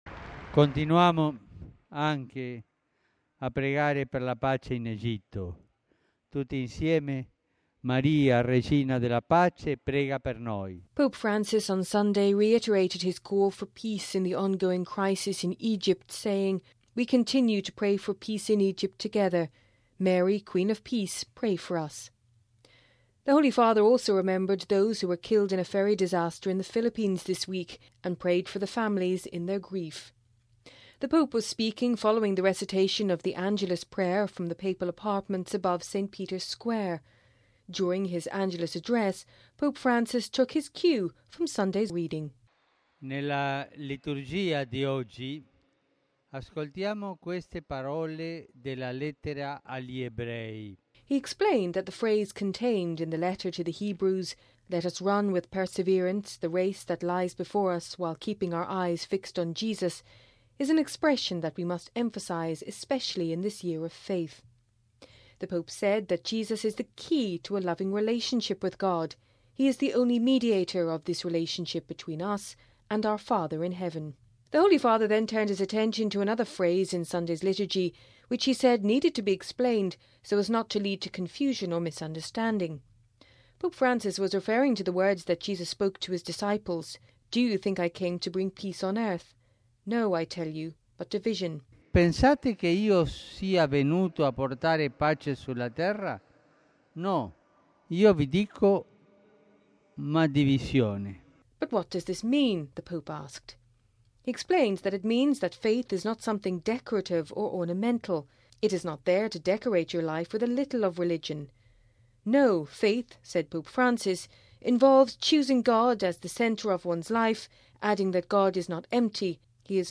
The Pope was speaking following the recitation of the Angelus prayer from the Papal Apartments above St Peter’s Square.